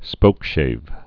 (spōkshāv)